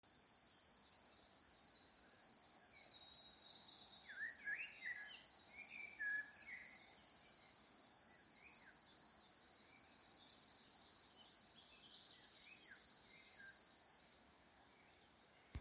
Putni -> Ķauķi ->
Upes ķauķis, Locustella fluviatilis
StatussDzied ligzdošanai piemērotā biotopā (D)